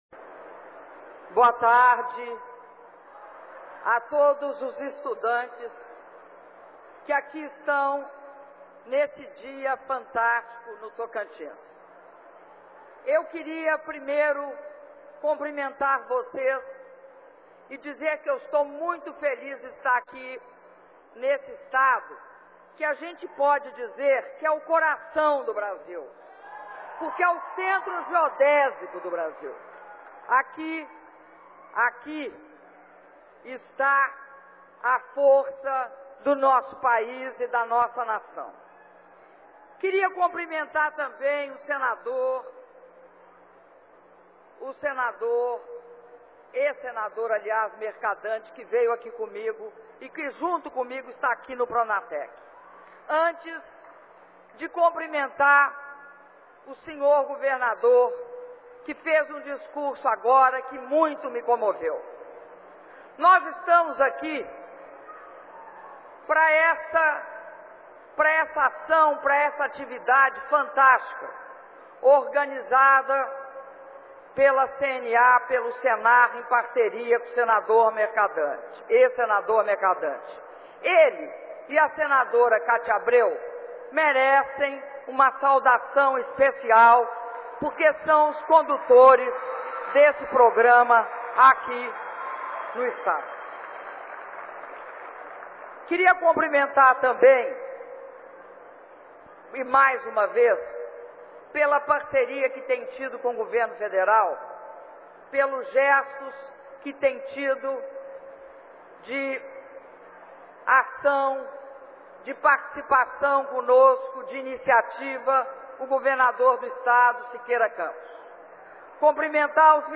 Discurso da Presidenta da República, Dilma Rousseff, na cerimônia de entrega de certificados aos formandos dos cursos do Programa Nacional de Acesso ao Ensino Técnico e ao Emprego - Pronatec - Palmas/TO